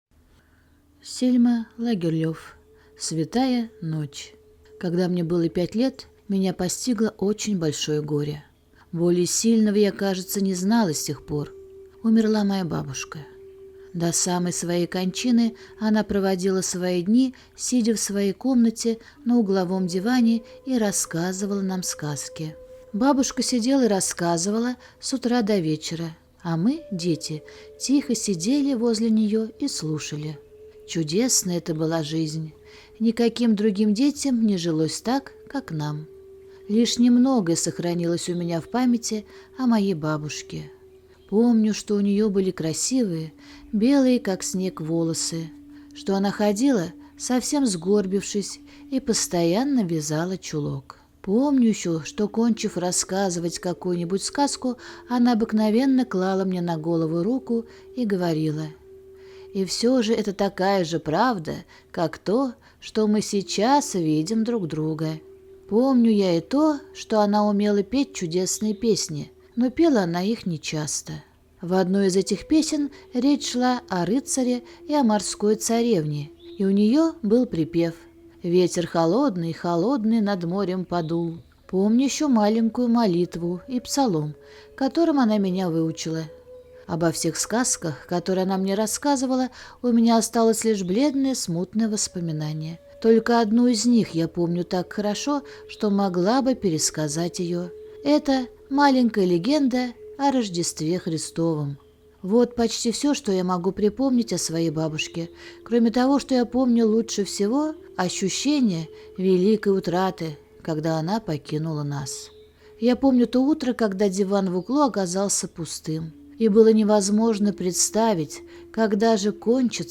Святая ночь - аудиосказка Лагерлеф С. Рассказ про замечательную бабушку, которая рассказывала удивительные сказки и истории своим внукам.